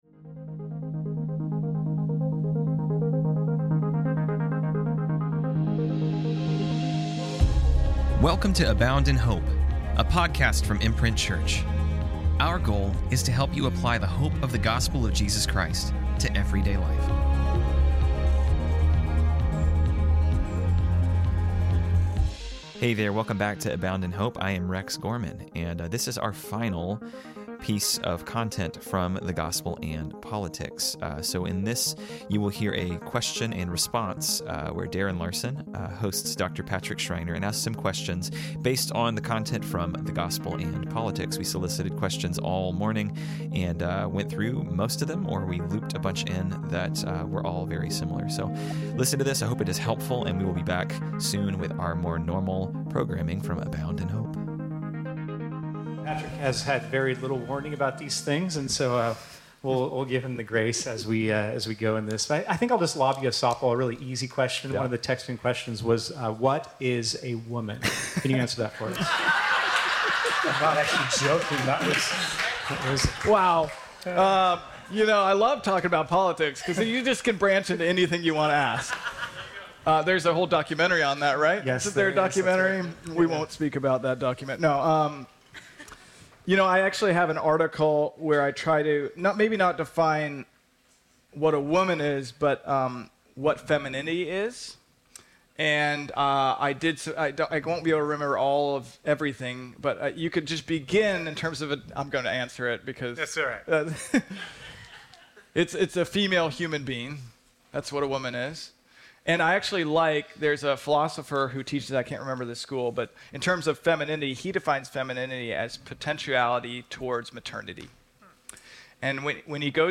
This week, we are airing the final session from The Gospel& Politics, a conference we put on in Summer of 2024.